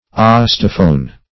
Search Result for " osteophone" : The Collaborative International Dictionary of English v.0.48: Osteophone \Os"te*o*phone\, n. [Gr.